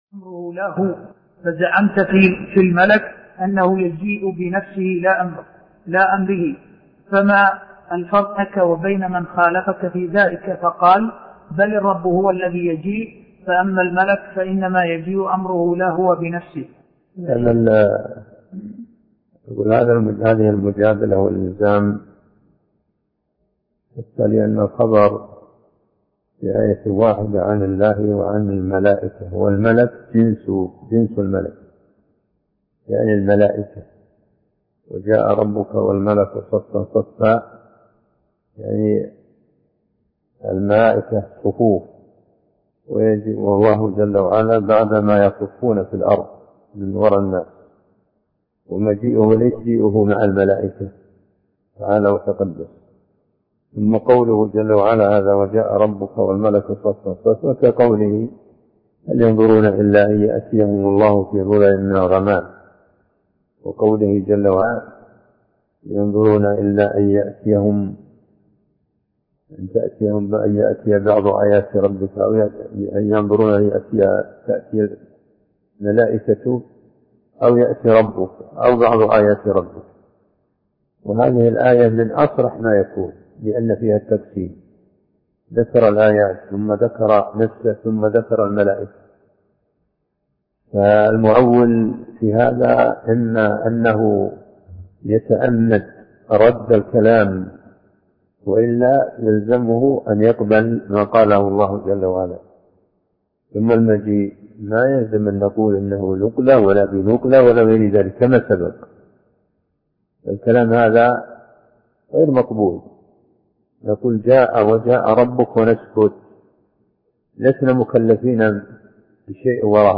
عنوان المادة الدرس (3)شرح كتاب التبصير في معالم الدين لابن جرير الطبري تاريخ التحميل السبت 31 ديسمبر 2022 مـ حجم المادة 35.84 ميجا بايت عدد الزيارات 251 زيارة عدد مرات الحفظ 101 مرة إستماع المادة حفظ المادة اضف تعليقك أرسل لصديق